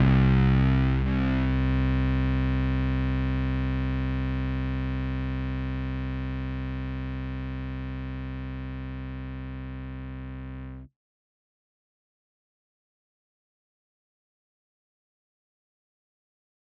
Bass (Running Out Of Time).wav